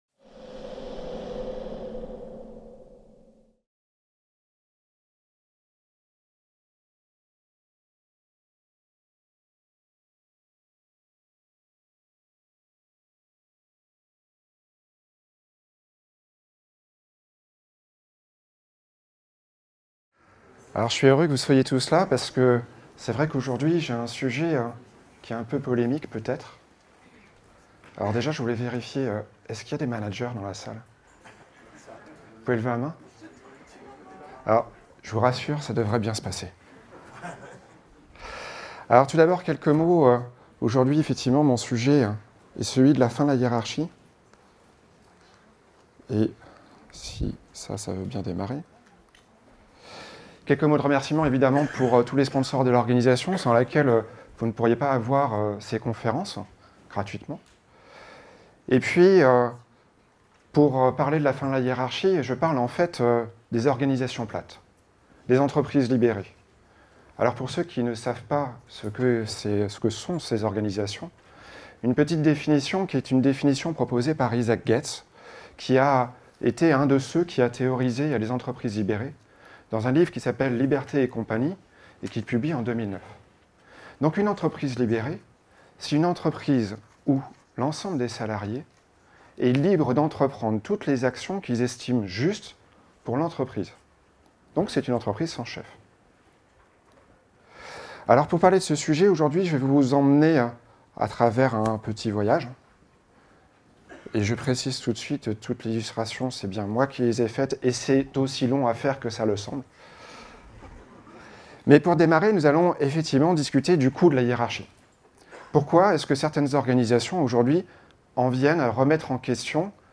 La conférence